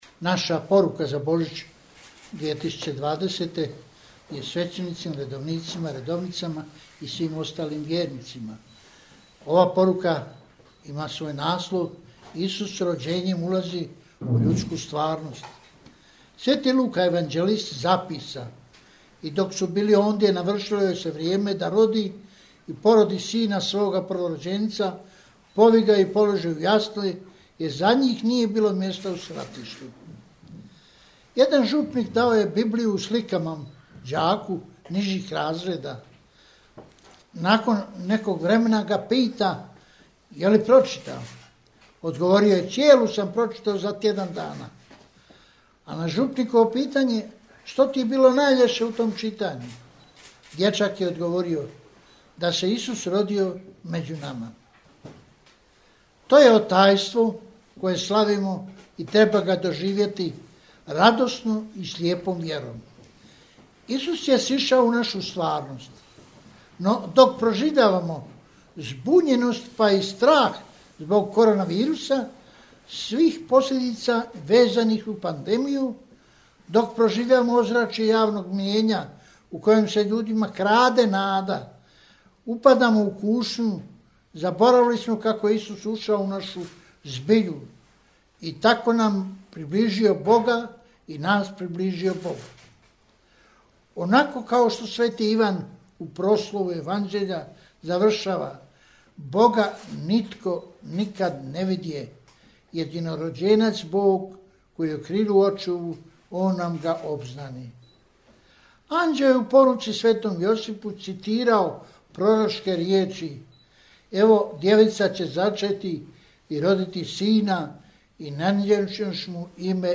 AUDIO: BOŽIĆNA PORUKA KARDINALA PULJIĆA